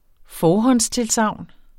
Udtale [ ˈfɒːhʌns- ]